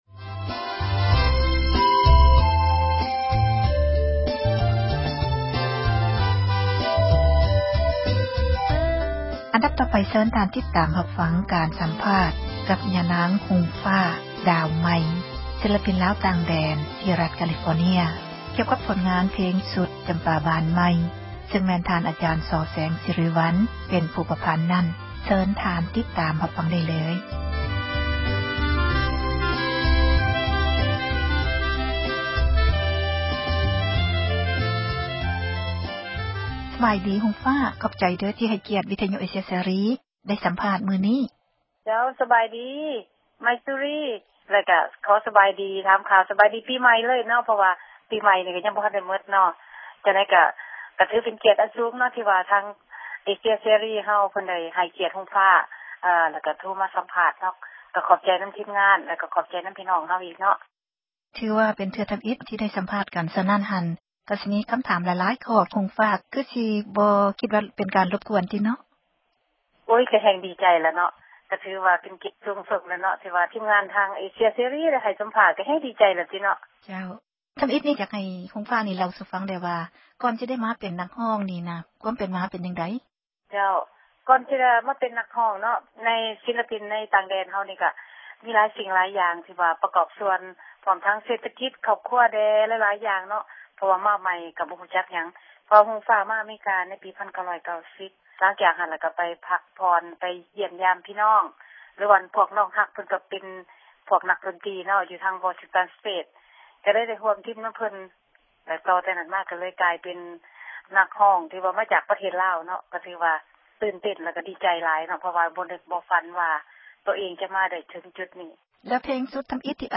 ສັມພາດນັກຮ້ອງ